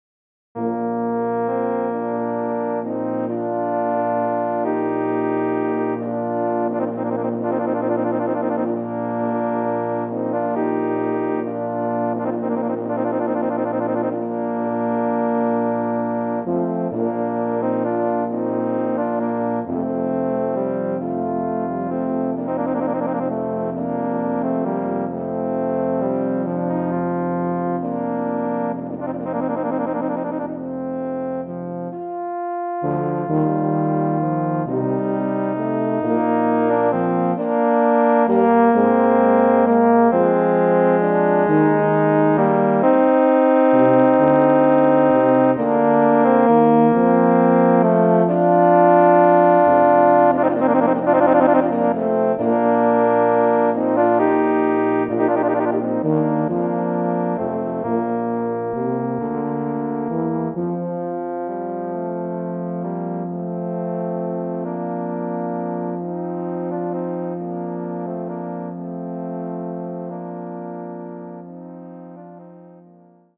Voicing: Tuba Quartet (EETT)